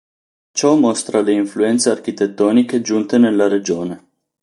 mó‧stra
/ˈmos.tra/